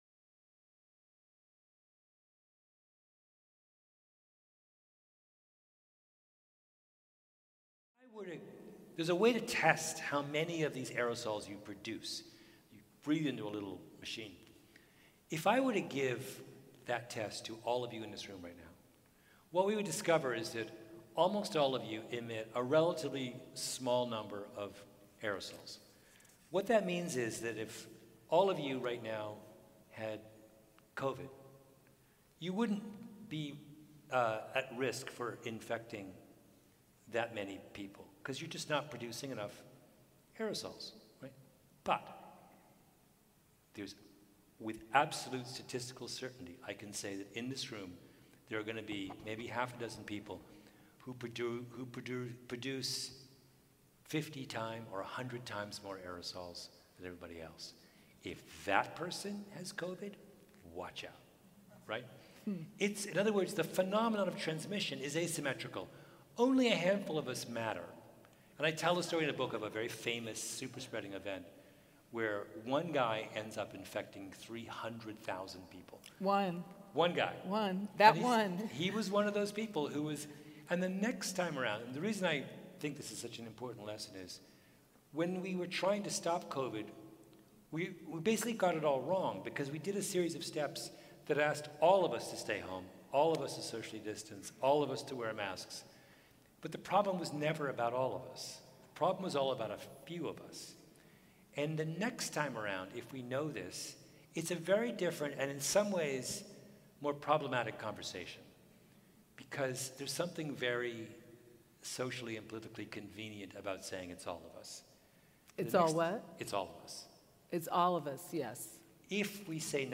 Congregation Rodeph Shalom 615 North Broad Street, Philadelphia, PA 19123 Enter at 1339 Green Street, Philadelphia, PA 19123
The Author Events Series presents Malcolm Gladwell | Revenge of the Tipping Point